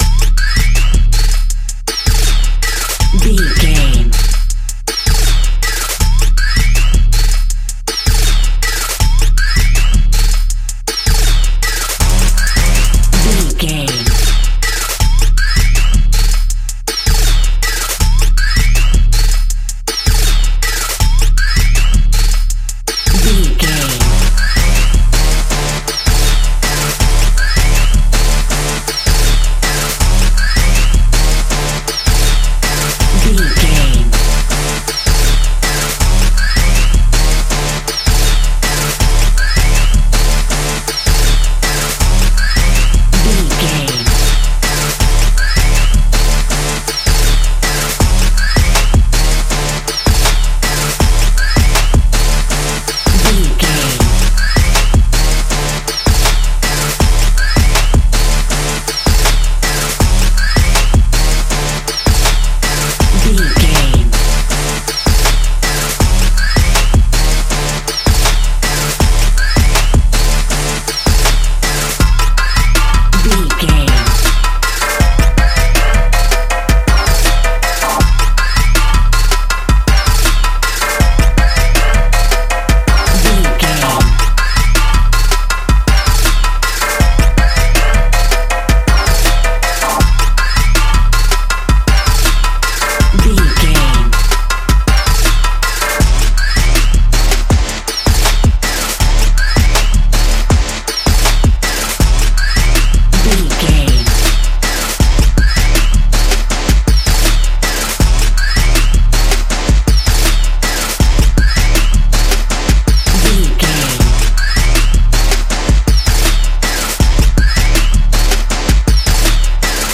Ionian/Major
Fast
bright
cheerful/happy
intense
lively
playful
uplifting